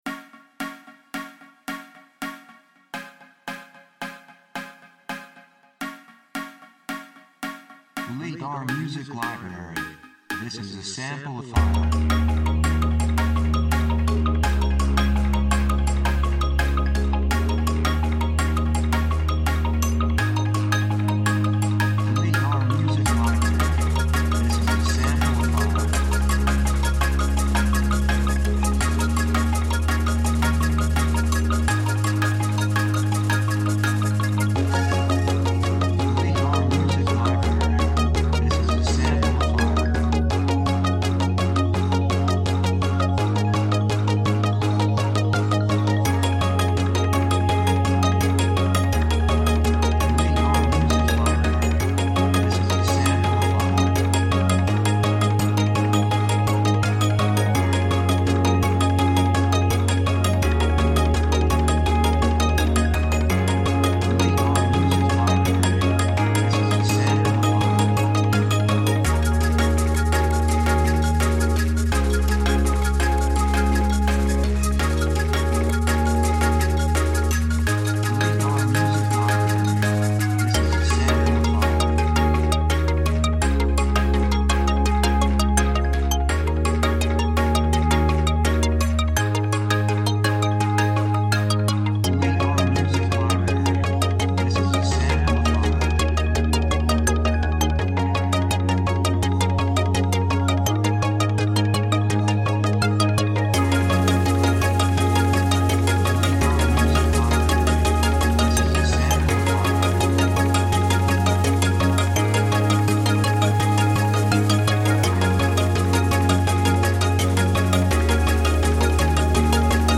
雰囲気エネルギッシュ, 幸せ, せわしない, 高揚感, 決意, 夢のような, 喜び
曲調ポジティブ
楽器シンセサイザー
サブジャンルドラムンベース
テンポとても速い